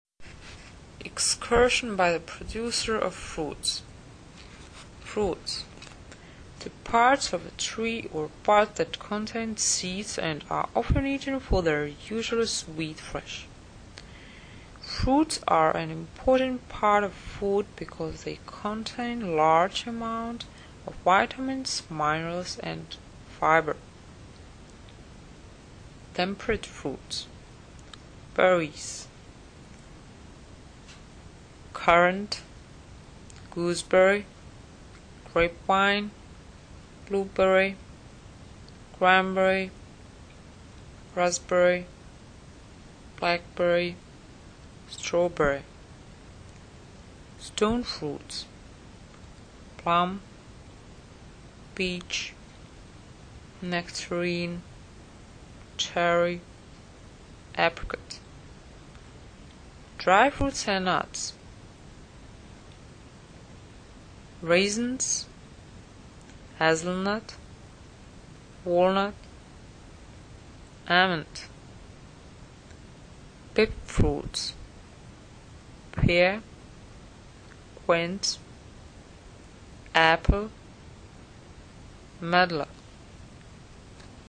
Nahrávka výslovnosti (*.MP3 soubor):